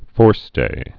(fôrstā)